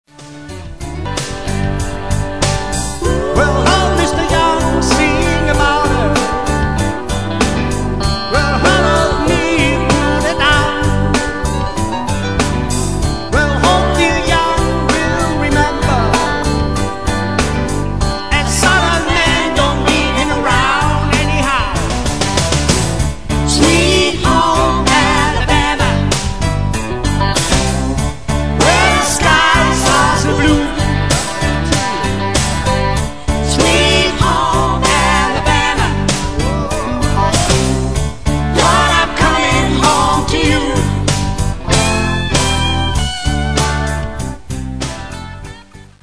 Das Pop/Rock-Musik Programm